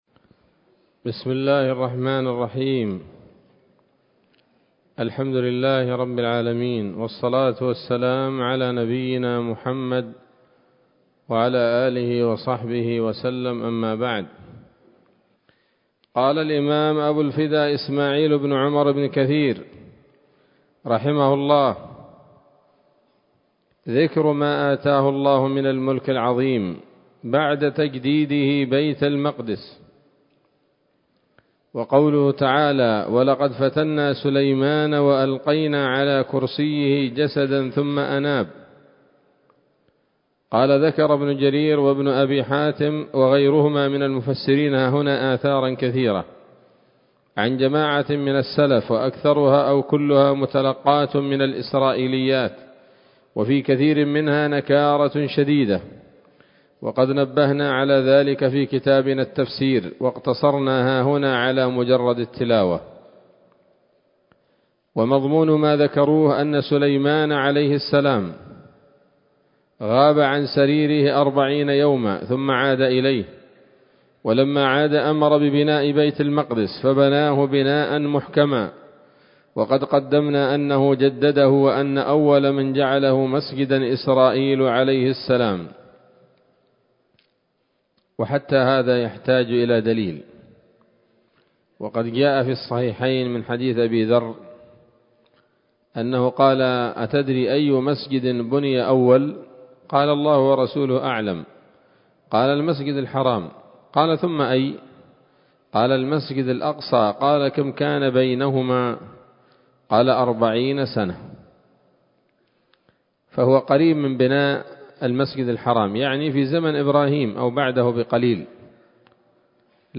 ‌‌الدرس الرابع والعشرون بعد المائة من قصص الأنبياء لابن كثير رحمه الله تعالى